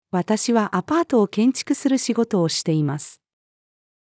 私はアパートを建築する仕事をしています。-female.mp3